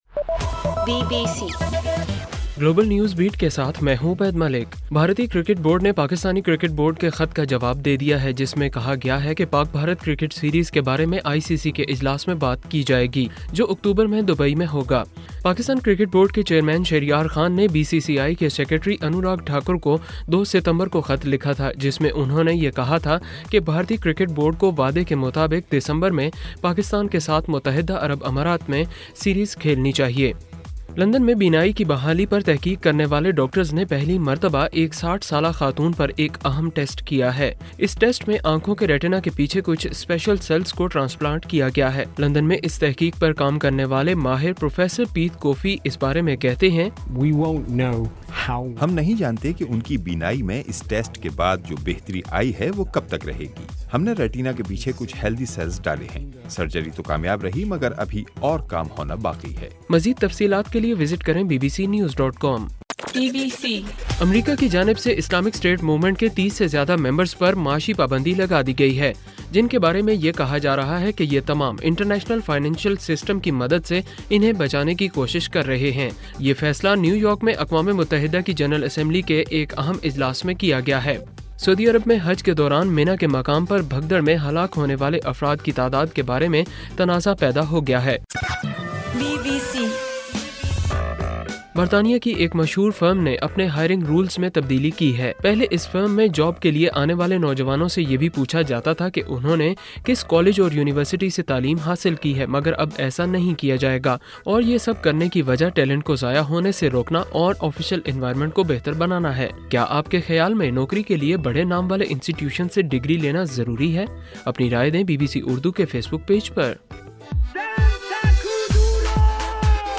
ستمبر 29: رات 10 بجے کا گلوبل نیوز بیٹ بُلیٹن